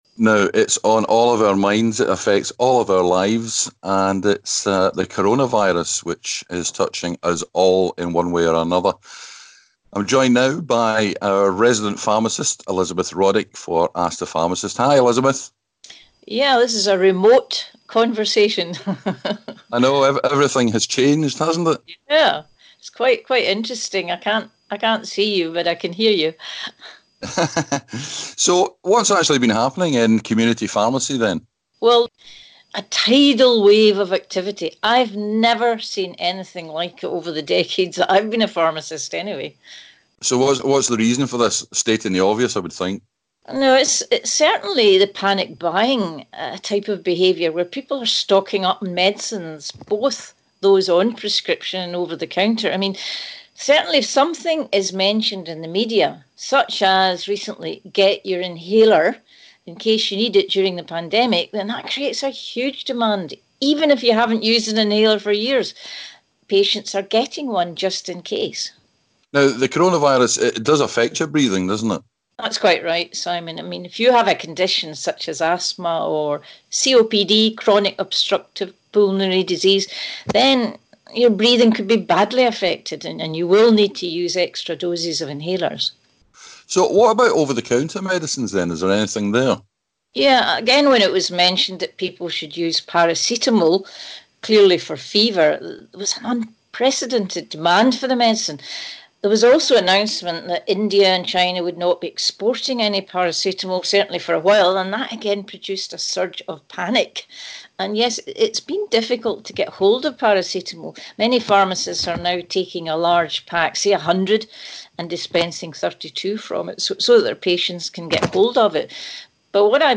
In this time of uncertainty, we thought it would be good to hear from our local pharmacist and find out how to keep ourselves and our pharmacies in good shape during this outbreak.